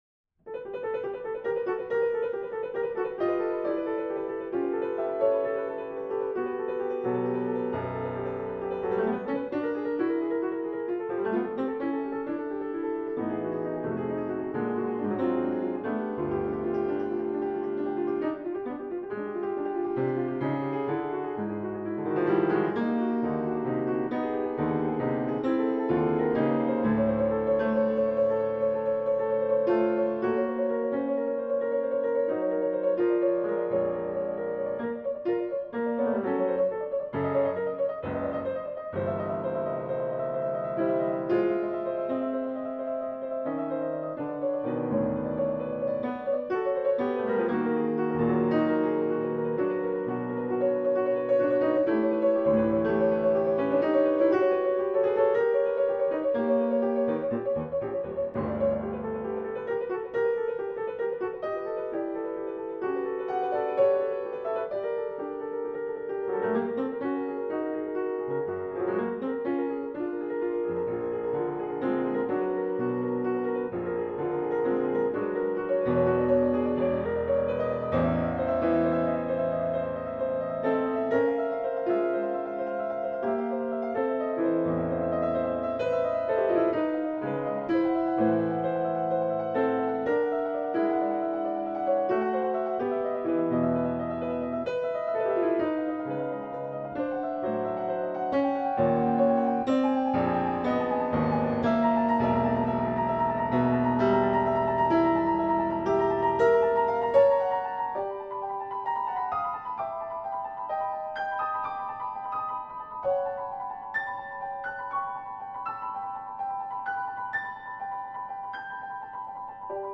Audio File - Prelude Op. 11 no. 6 of The piano works of Raymond Hanson: discovery, exploration and reflection